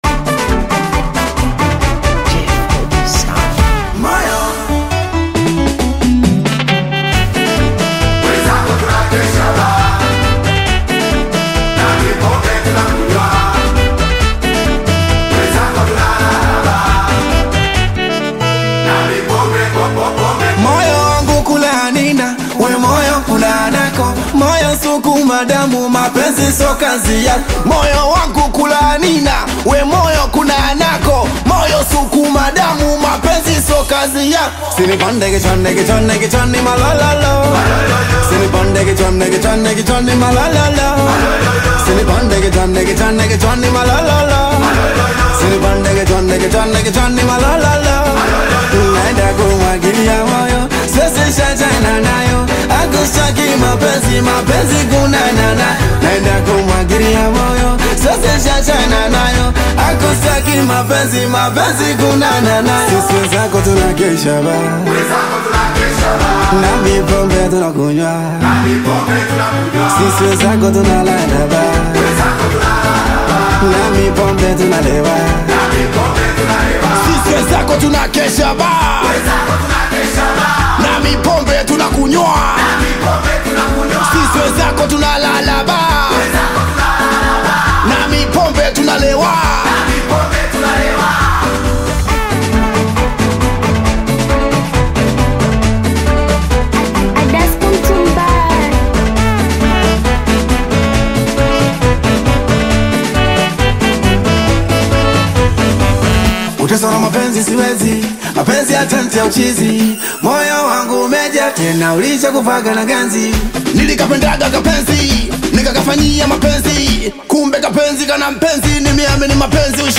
Singeli music track
Tanzanian Bongo Flava artist